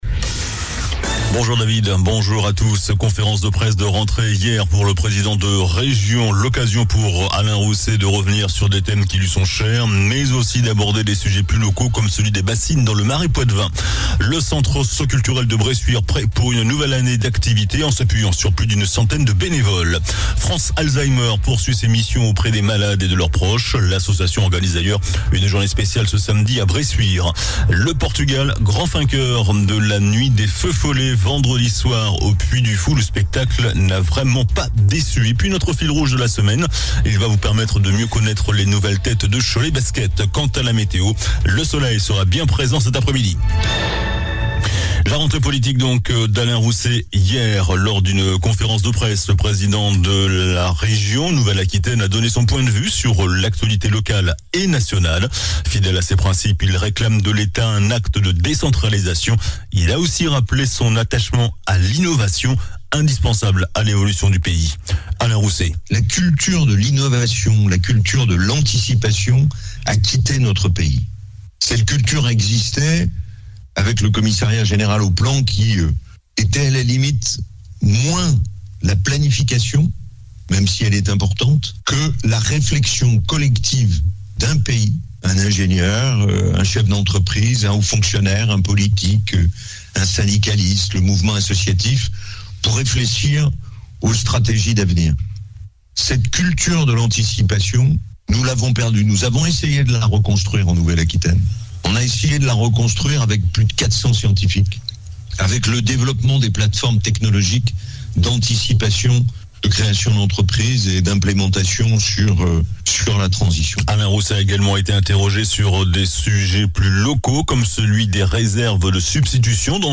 JOURNAL DU MARDI 20 SEPTEMBRE ( MIDI )